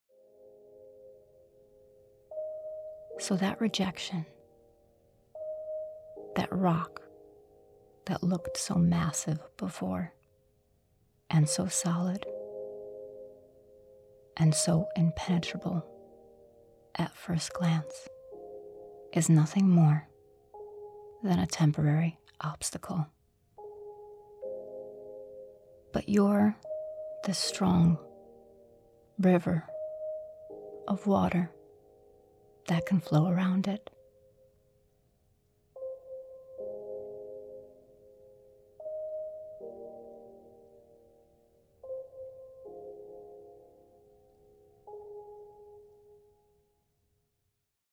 In this series, we use guided meditation to tackle areas in life that it would be best for us to release.  In Volume I, we work on 3 three common themes:  (1) Letting Go of Nervousness, Anxiety and Fear, (2) Letting Go of Rejection, and (3) Letting Go of the Outcome.